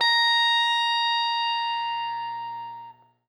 SPOOKY    AY.wav